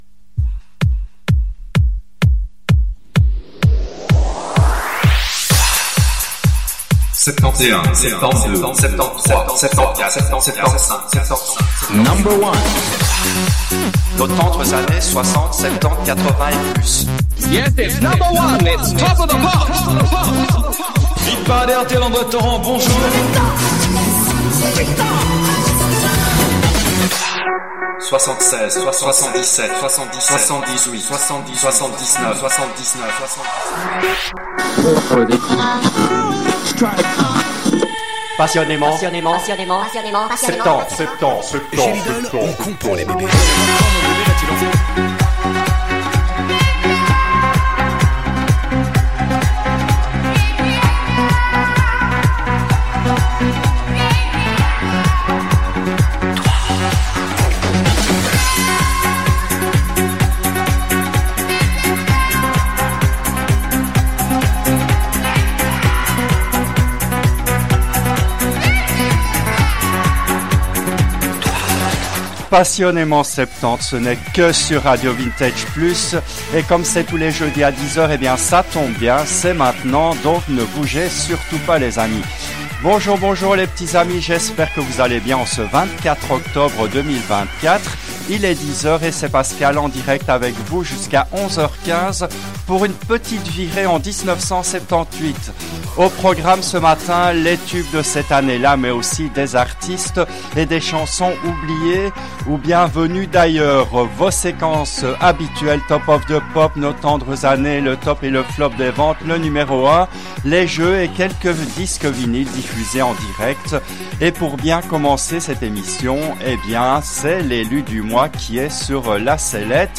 L’émission a été diffusée en direct le jeudi 24 octobre 2024 à 10h depuis les studios belges de RADIO RV+.